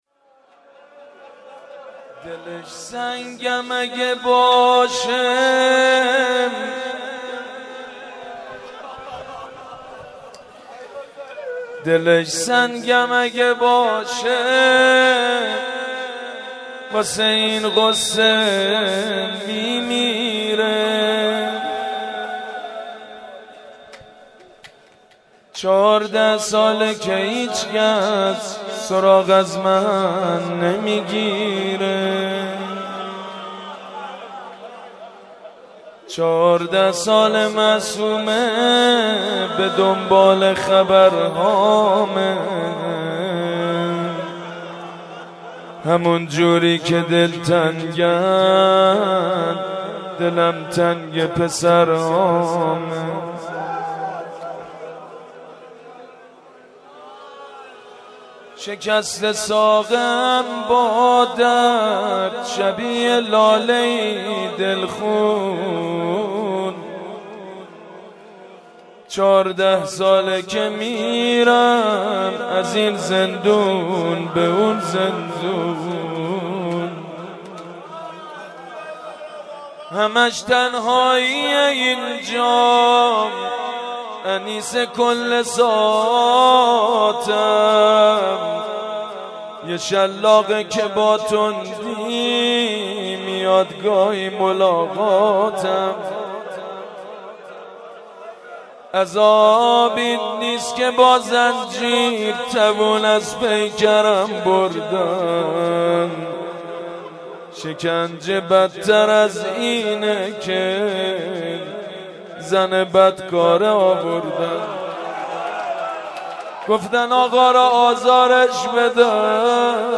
مناسبت : شهادت امام موسی‌کاظم علیه‌السلام
مداح : سیدمجید بنی‌فاطمه قالب : روضه